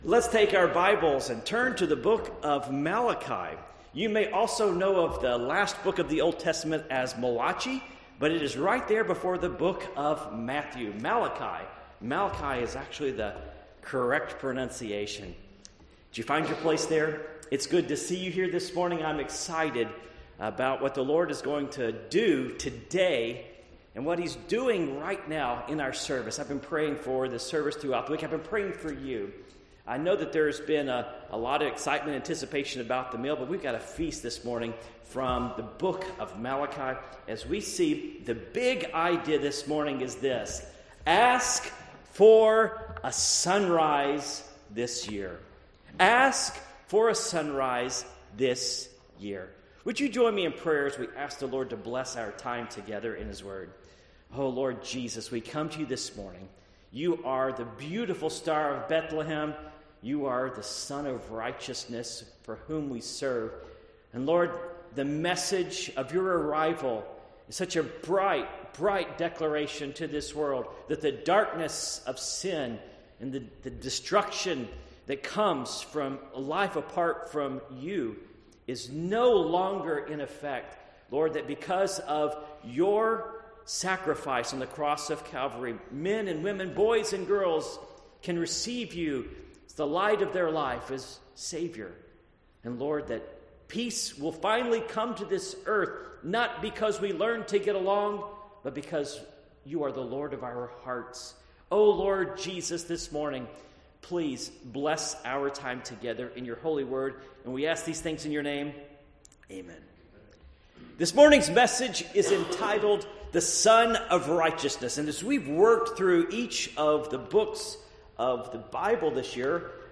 Passage: Malachi 4:1-6 Service Type: Morning Worship